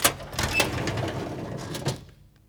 DRAWER MN OP.WAV